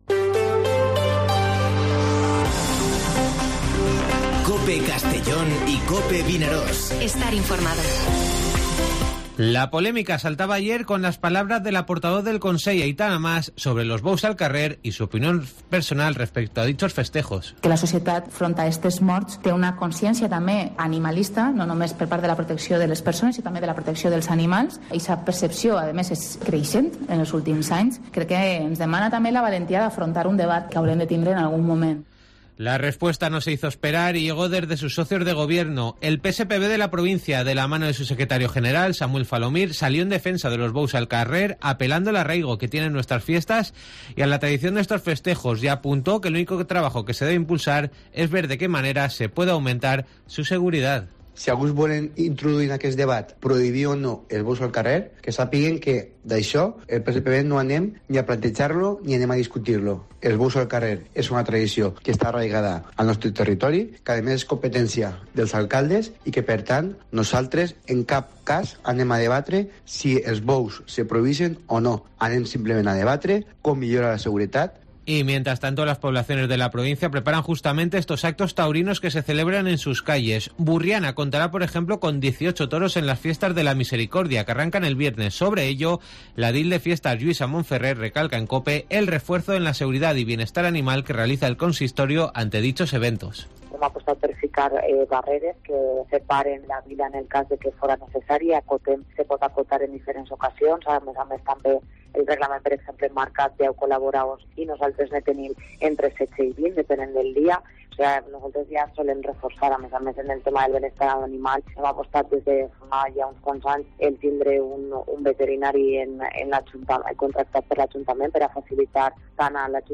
Informativo Mediodía COPE en Castellón (31/08/2022)